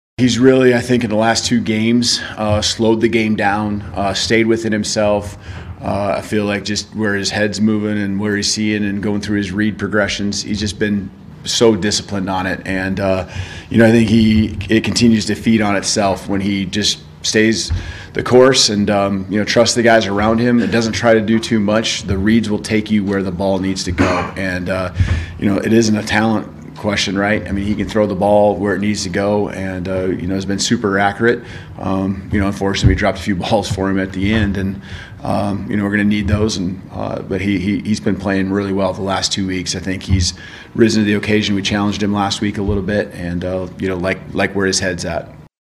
Following the game, coach Kalen DeBoer was asked about his quarterback’s play.